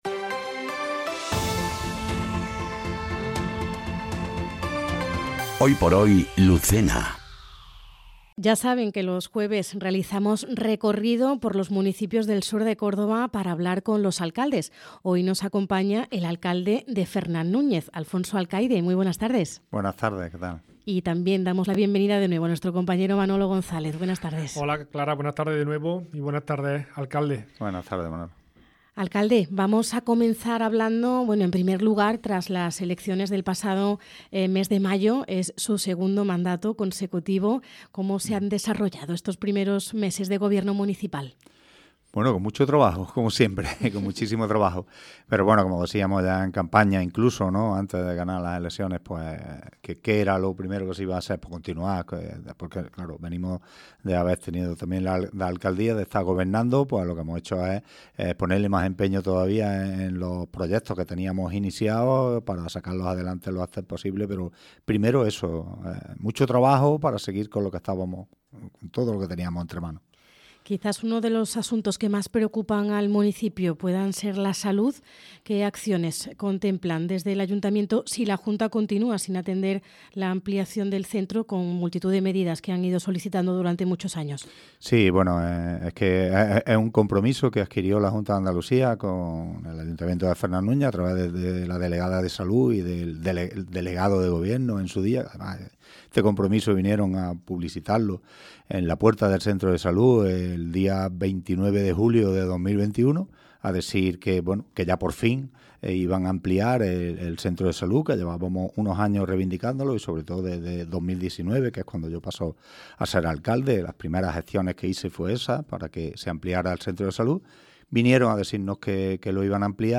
ENTREVISTA | Alfonso Alcaide, alcalde de Fernán Núñez - Andalucía Centro